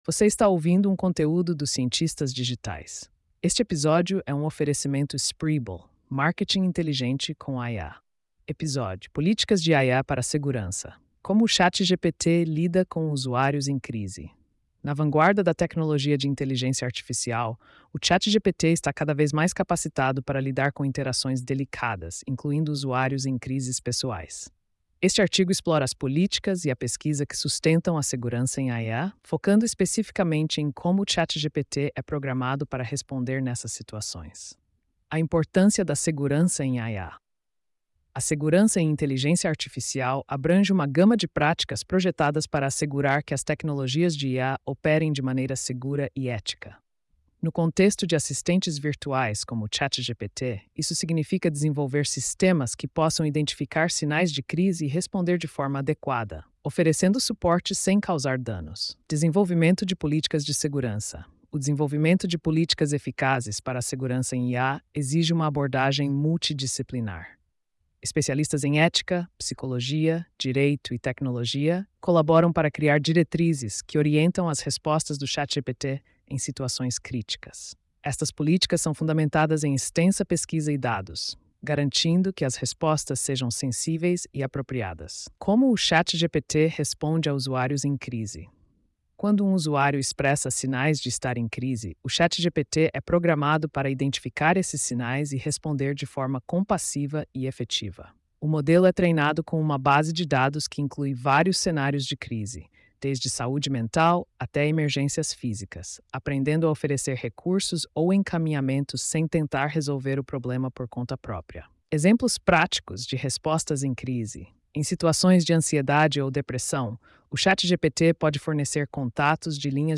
post-4570-tts.mp3